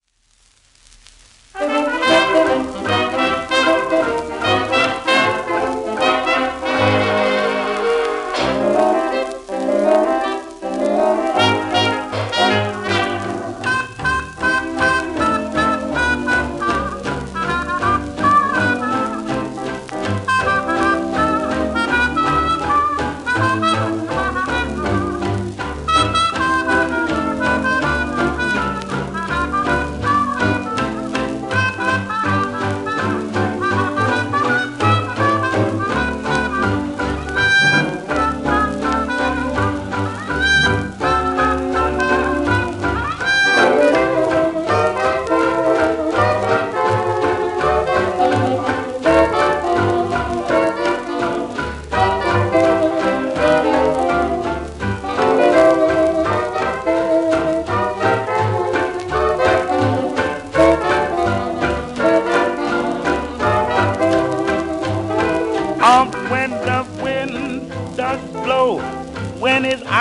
1933年録音